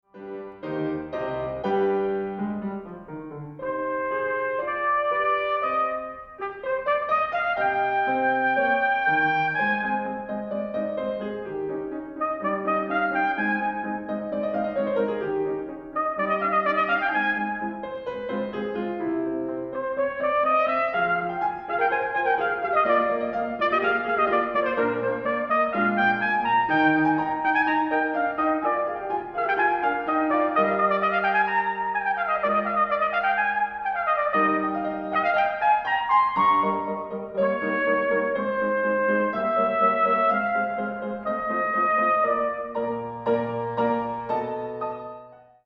Haydn: Trumpet Concerto, Mvt. 1 (Excerpt)